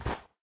snow2.ogg